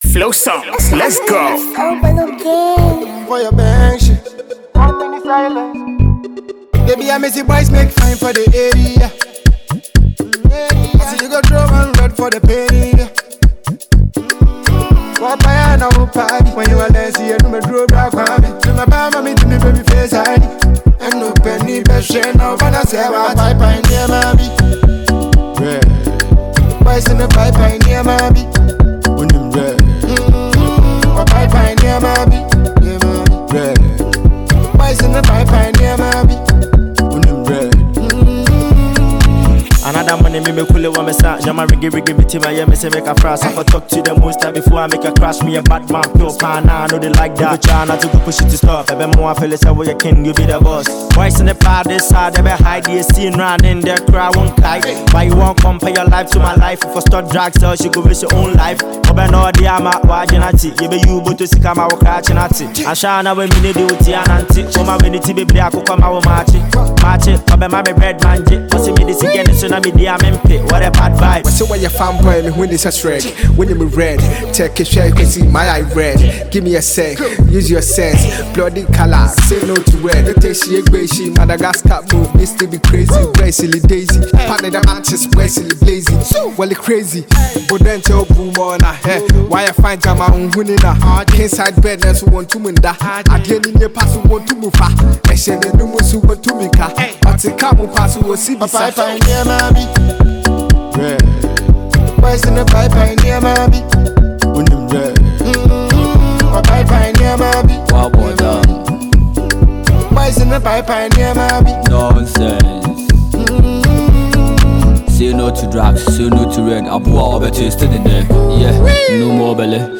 It is a catchy and lively melody.
Highlife and Afrobeat